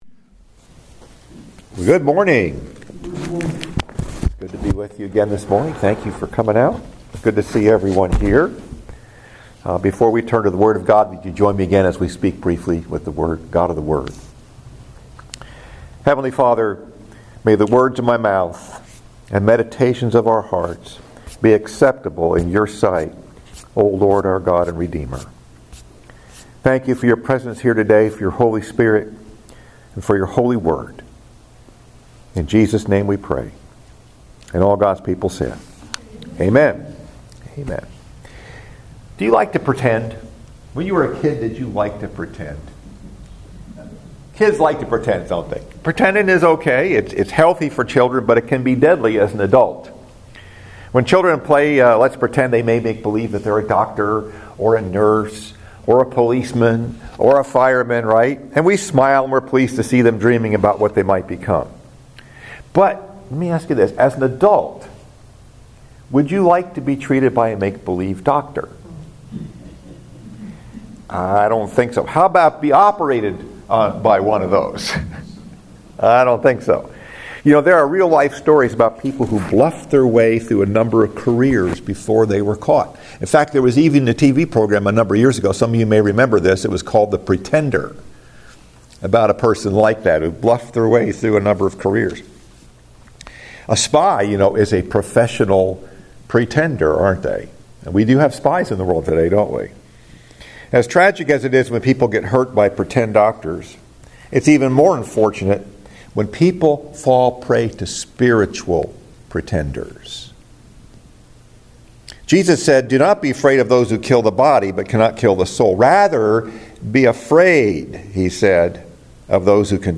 Message: “A Good Defense” Scripture: Jude 3, 4